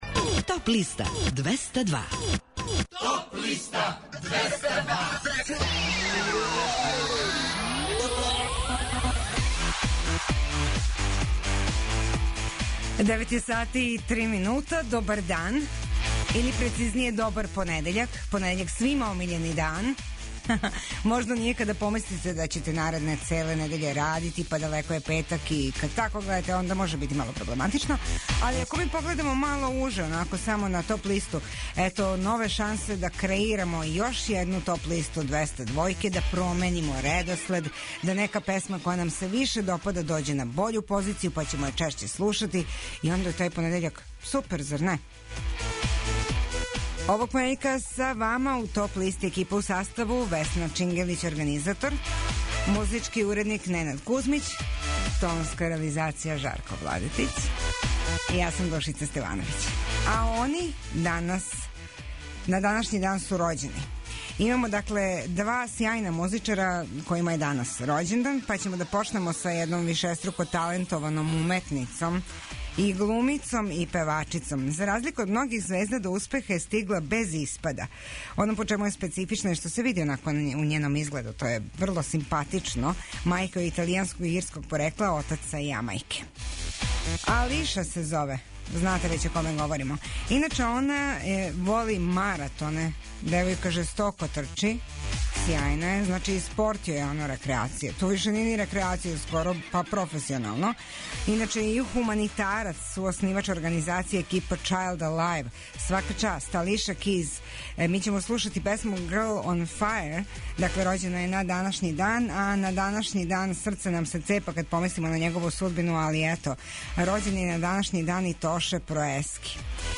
преузми : 19.50 MB Топ листа Autor: Београд 202 Емисија садржи више различитих жанровских подлиста.